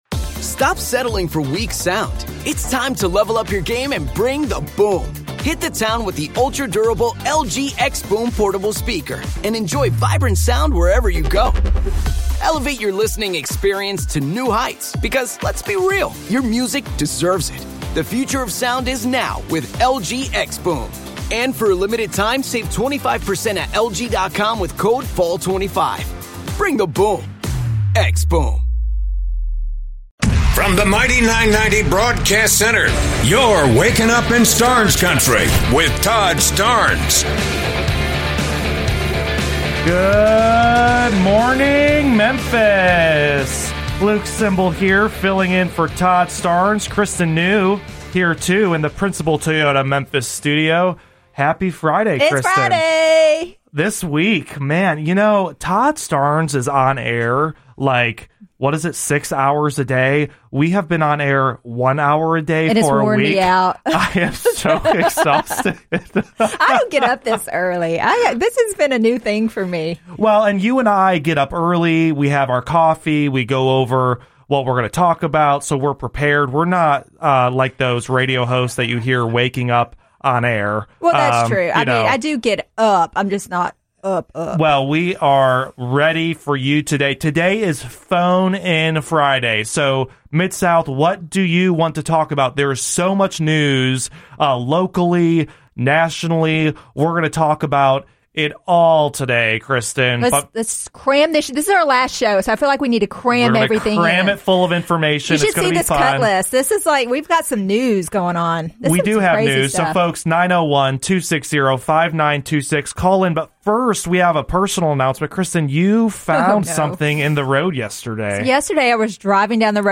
Phone in Friday!!!!